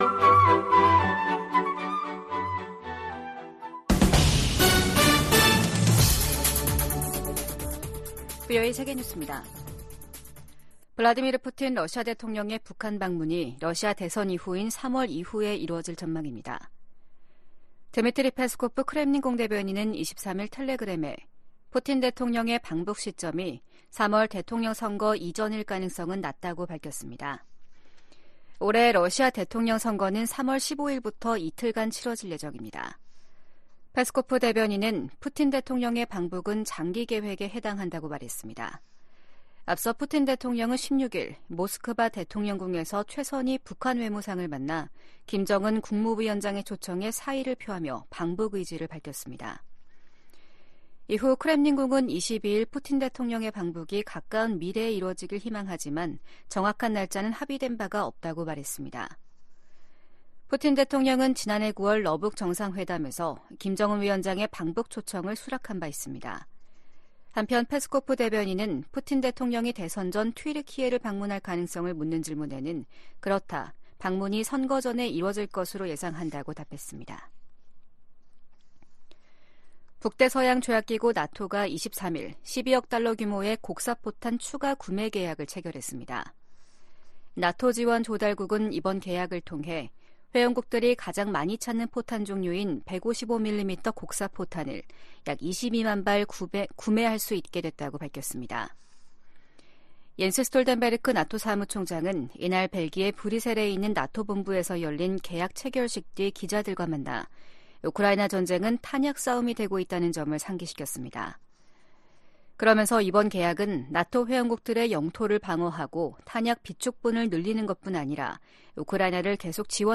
VOA 한국어 아침 뉴스 프로그램 '워싱턴 뉴스 광장' 2024년 1월 24일 방송입니다. 북한-러시아 군사협력은 역내 안정과 국제 비확산 체제를 약화시킨다고 유엔 주재 미국 차석대사가 지적했습니다. 백악관이 북한과 러시아의 무기 거래를 거론하며 우크라이나에 대한 지원의 필요성을 강조했습니다. 중국에 대한 보편적 정례 인권 검토(UPR)를 앞두고 유엔과 유럽연합(EU)이 탈북민 강제 북송 중단을 중국에 촉구했습니다.